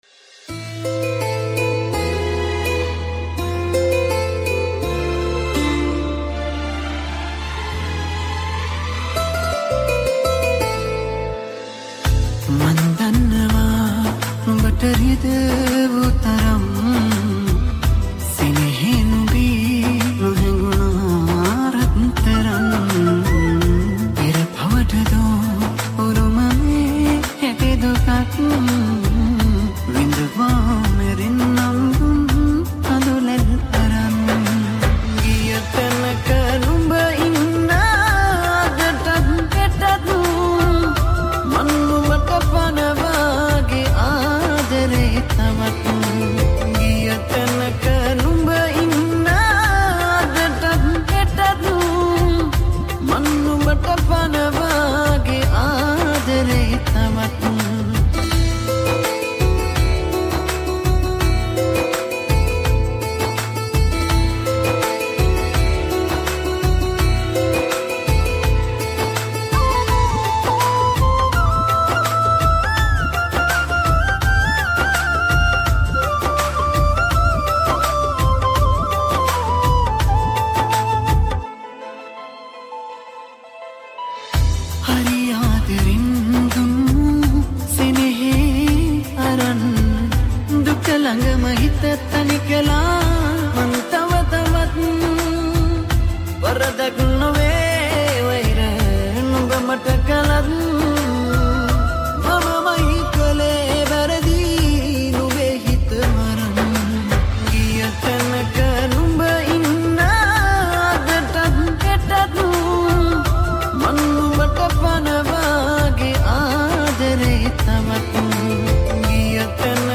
High quality Sri Lankan remix MP3 (3.6).